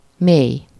Ääntäminen
Synonyymit songé long anus Ääntäminen Tuntematon aksentti: IPA: /pʁɔ.fɔ̃/ Haettu sana löytyi näillä lähdekielillä: ranska Käännös Ääninäyte Adjektiivit 1. mély Suku: m .